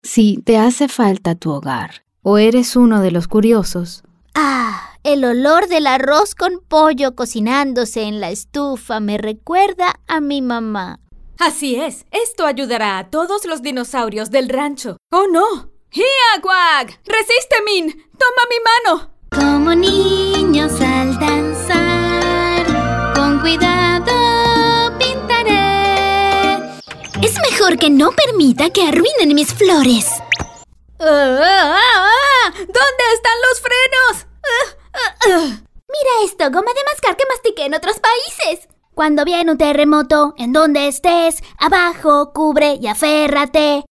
cartoon, character, foreign-language, fuzzy, genuine, high-energy, kooky, perky, real, spanish-showcase